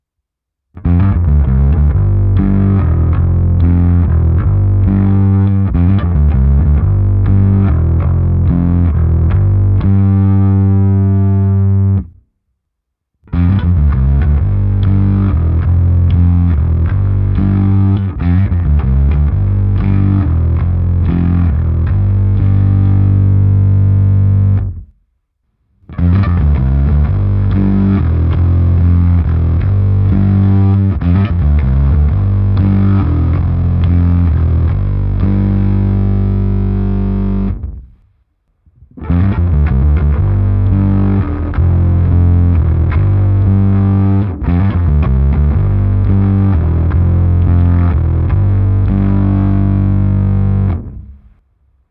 Zvuk má vysloveně chrčivé lampové zkreslení, jemnější bzučivý overdrive je prakticky nemožné z toho dostat, ale k tomu zkrátka slouží jiné krabičky. Při stažení drivu na minimum lze zvuk jen lehce nakřápnout, ale další paleta zvuků je už s výrazným zkreslením. Nahrál jsem i ukázku několika různých zvuků, počínaje menším zkreslení směrem k těm divočejším. Použitá baskytara je Fender American Professional II Precision Bass V.